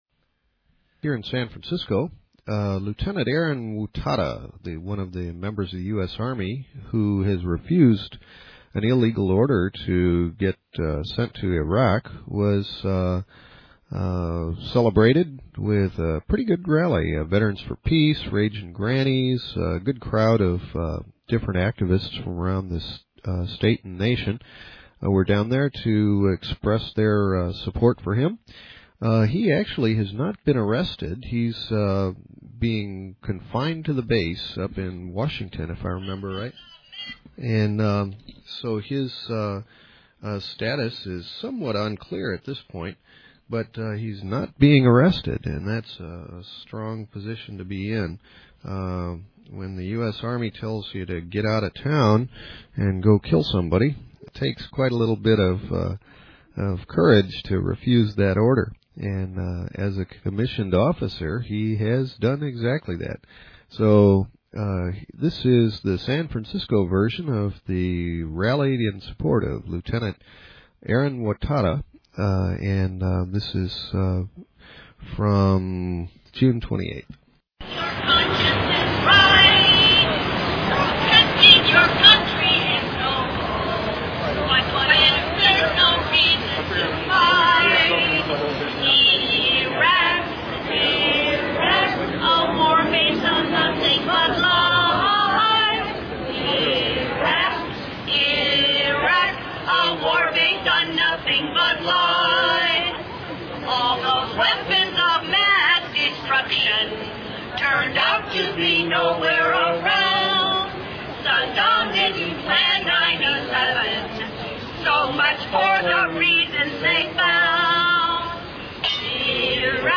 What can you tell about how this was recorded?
Justin Herman Plaza